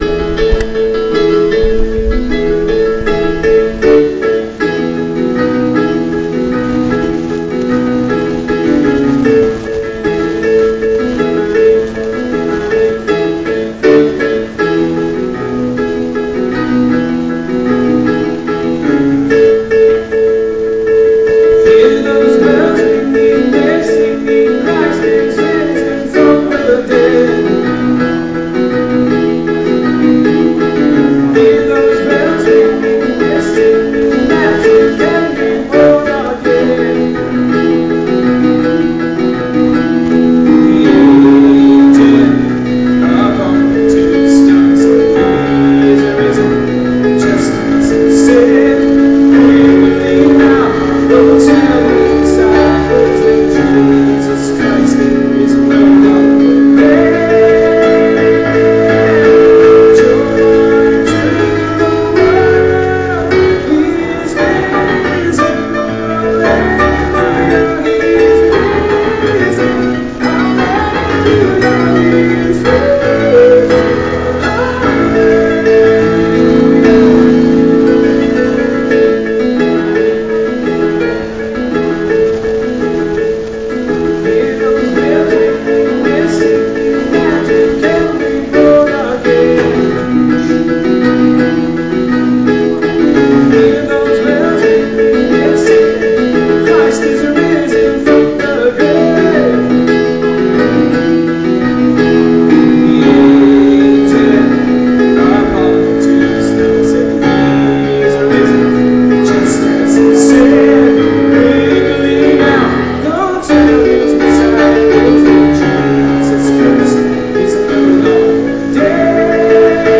Easter Sunday — Four Easter monologues